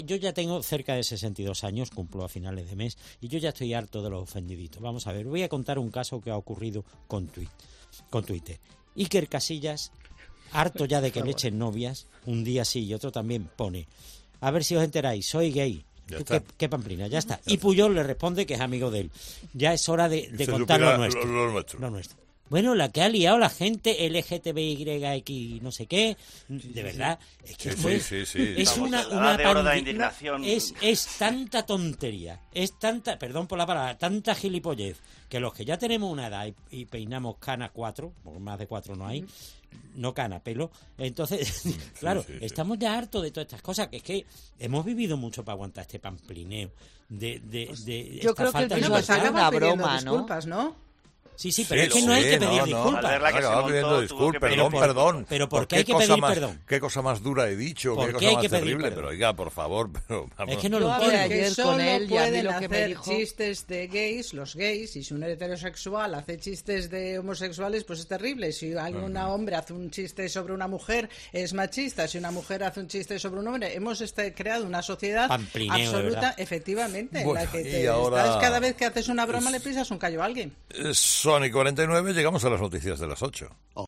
En la tertulia de 'Herrera en COPE' se ha explicado el motivo por el que Casillas habría publicado su mensaje original